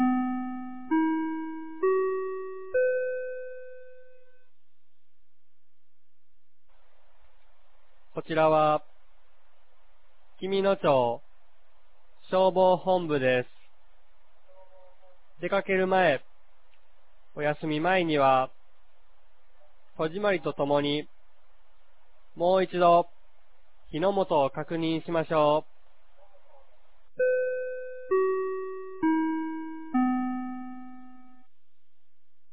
2023年09月02日 16時00分に、紀美野町より全地区へ放送がありました。